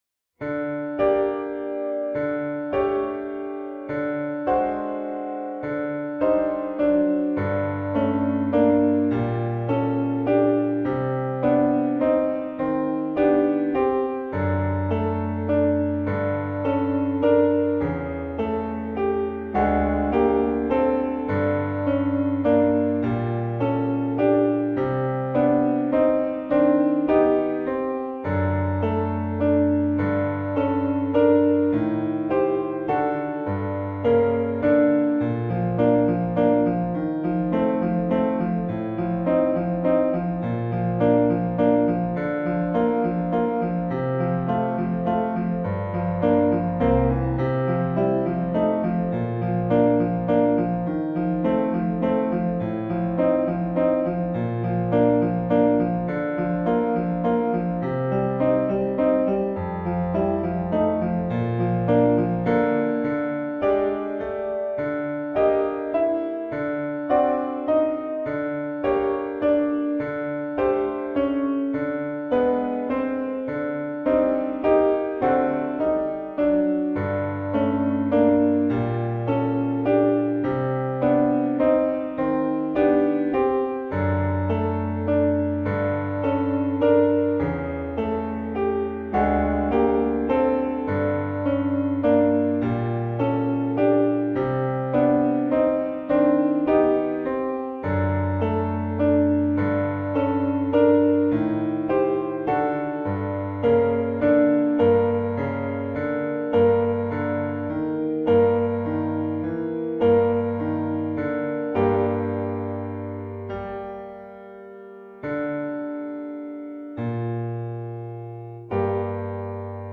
TROMBA SOLO • ACCOMPAGNAMENTO BASE MP3
sconosciuto Trombone